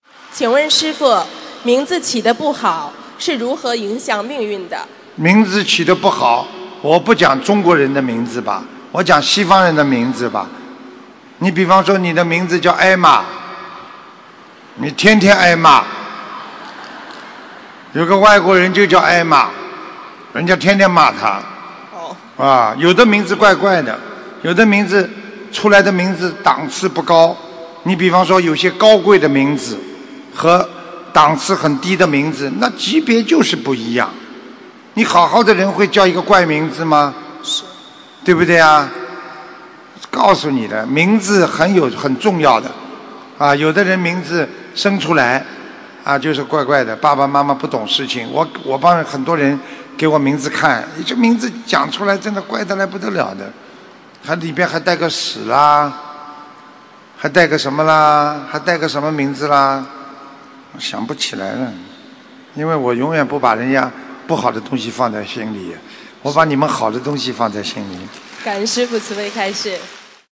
名字的好坏如何影响命运┃弟子提问 师父回答 - 2017 - 心如菩提 - Powered by Discuz!